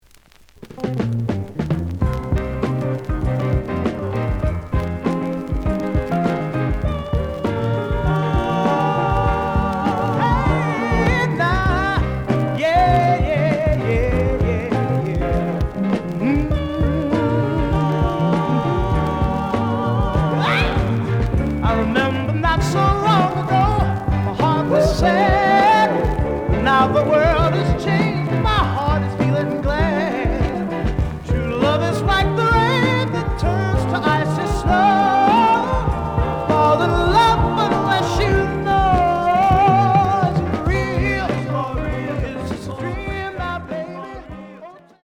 The audio sample is recorded from the actual item.
●Genre: Soul, 70's Soul
Slight affect sound.